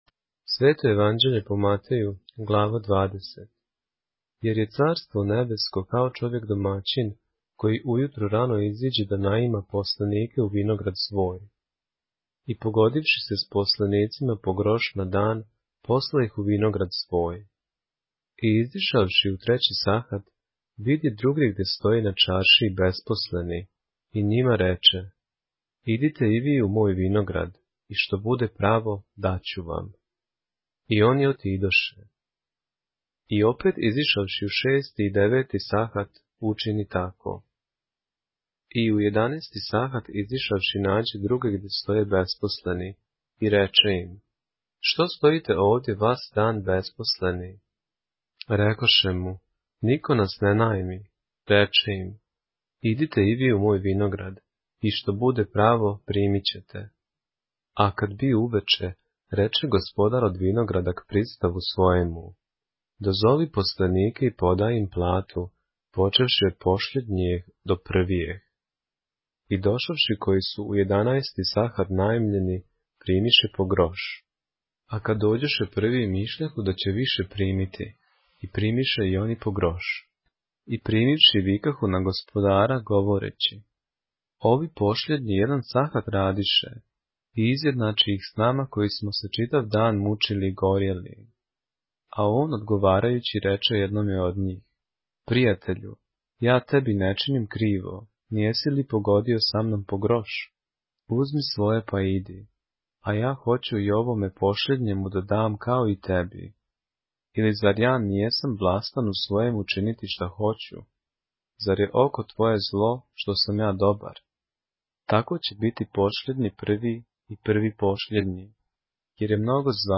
поглавље српске Библије - са аудио нарације - Matthew, chapter 20 of the Holy Bible in the Serbian language